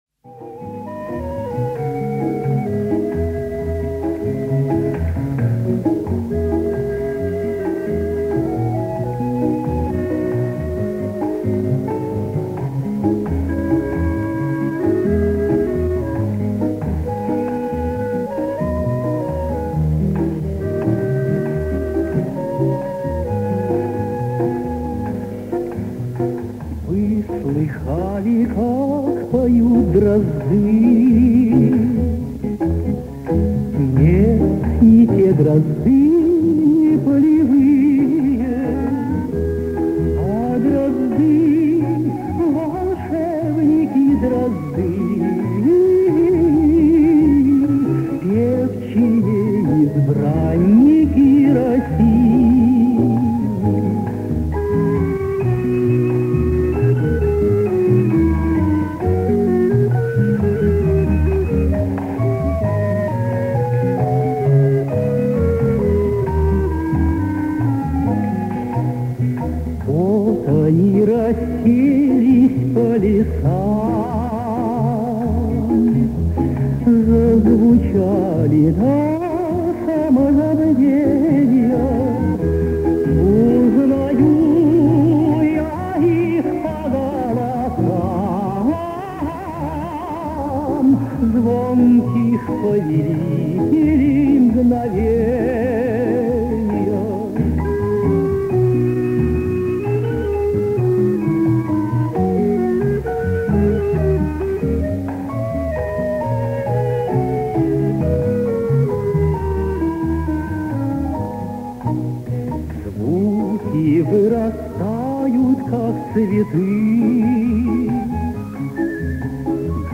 Певец (тенор).